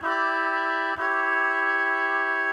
Index of /musicradar/gangster-sting-samples/95bpm Loops
GS_MuteHorn_95-E2.wav